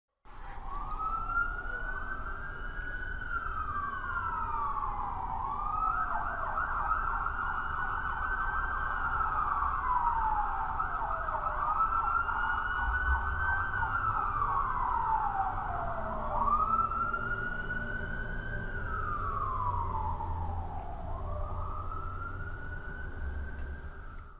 Police Siren Distant Sound Effect Free Download
Police Siren Distant